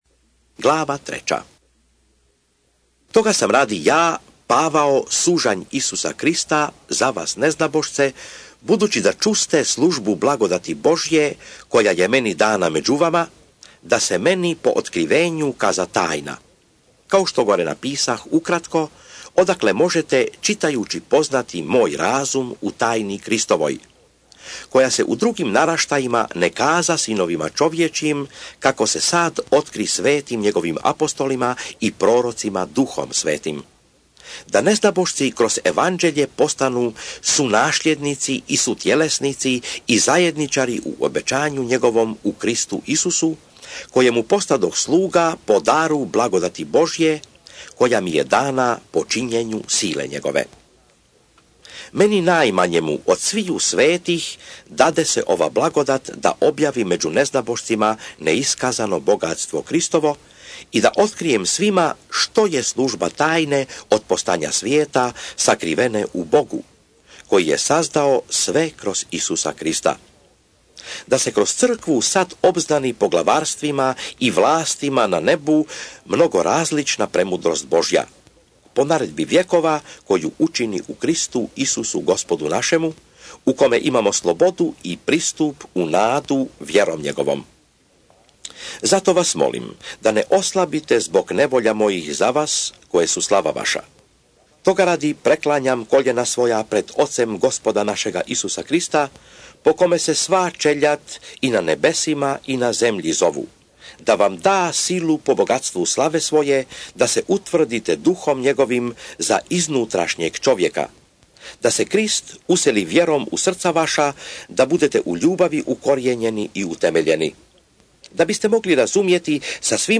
EFESCI(ČITANJE) - Bible expounded
SVETO PISMO – ČITANJE – Audio mp3 EFESCI glava 1 glava 2 glava 3 glava 4 glava 5 glava 6